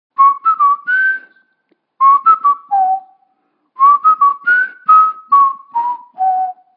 Свист Бенджамина